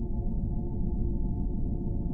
nopower.ogg